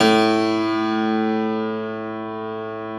53e-pno05-A0.wav